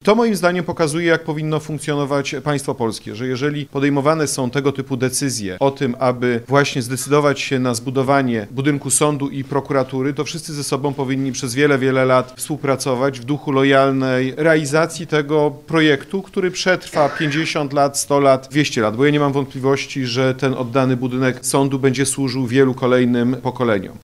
Szef Ministerstwa Sprawiedliwości, prokurator generalny Adam Bodnar uczestniczył w środę (27.11) w otwarciu nowego budynku sądu i prokuratury rejonowej w Opolu Lubelskim.